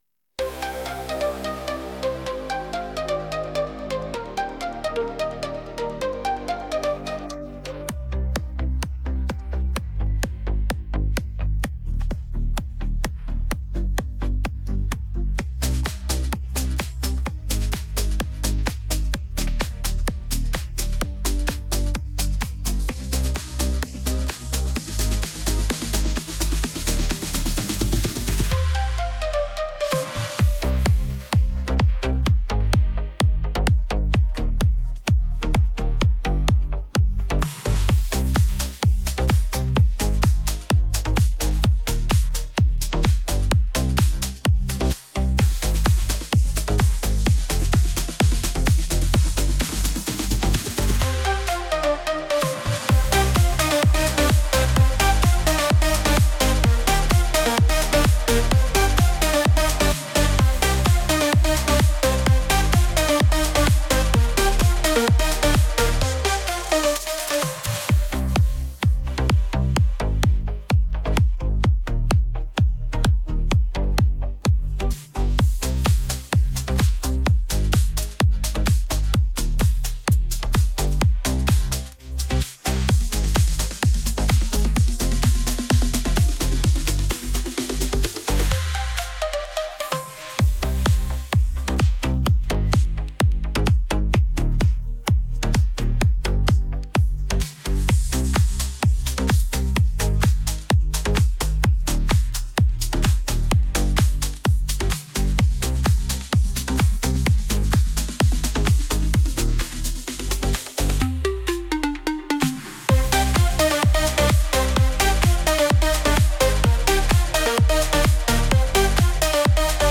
Скачать минус детской песни
минусовка